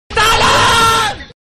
Efek Suara Meme Tolong... Mp3 | Download
Kategori: Suara viral
Keterangan: Efek suara meme Tolong.../ Too long... Ngakak lucu Indonesia viral, sering digunakan untuk edit video.
efek-suara-meme-tolong-id-www_tiengdong_com.mp3